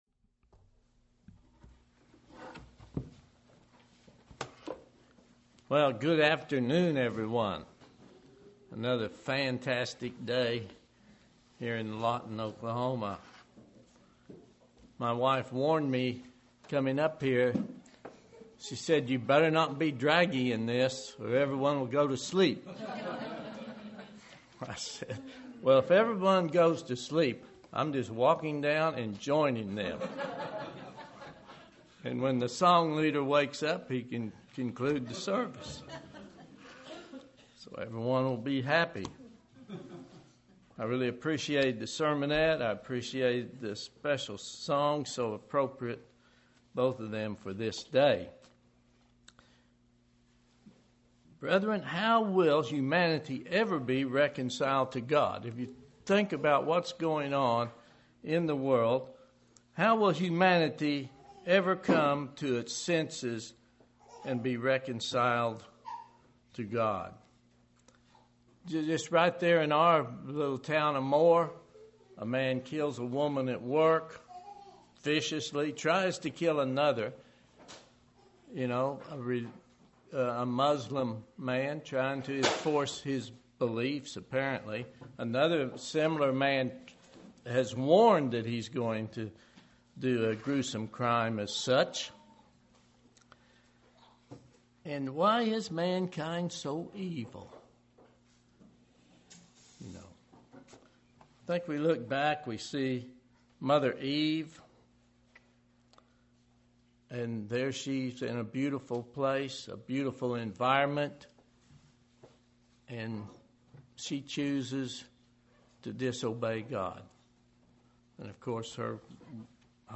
This sermon discusses how the world will be reconciled to God.